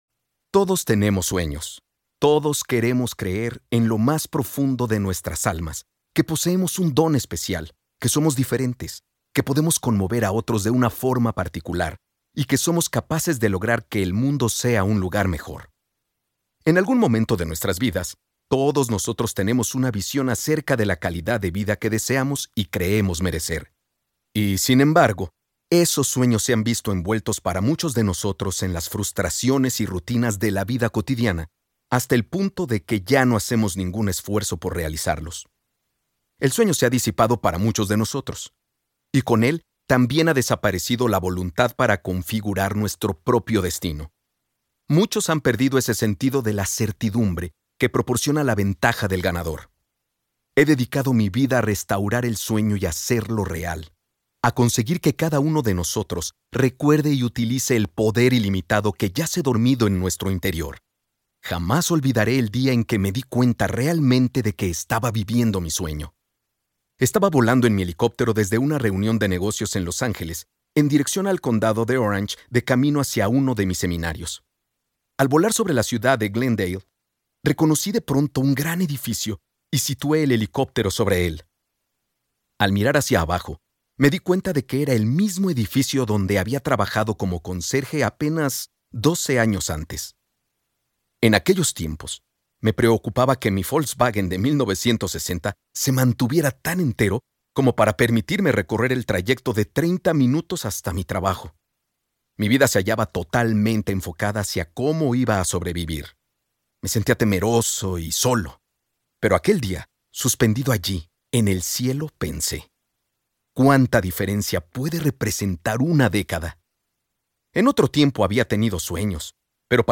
👇 MIRA LA LISTA COMPLETA Y ESCUCHA LA MUESTRA DE CADA AUDIOLiBRO 👇